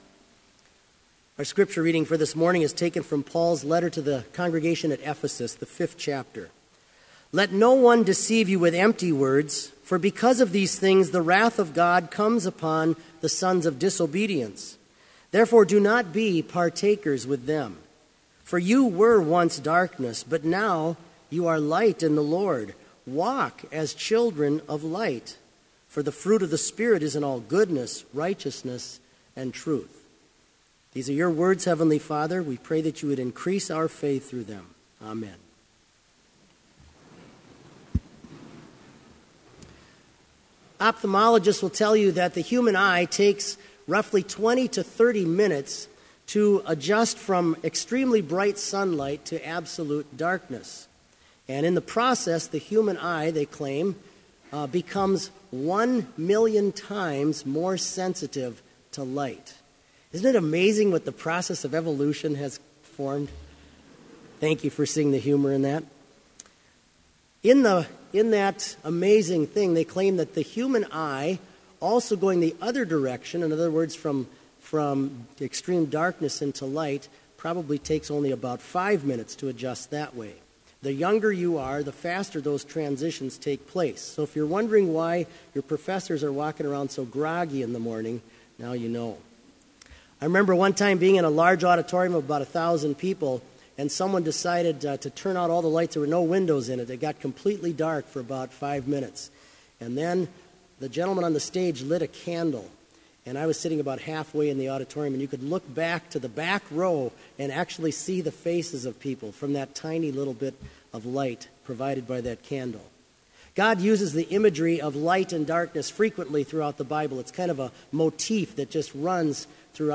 Sermon Only
This Chapel Service was held in Trinity Chapel at Bethany Lutheran College on Friday, October 23, 2015, at 10 a.m. Page and hymn numbers are from the Evangelical Lutheran Hymnary.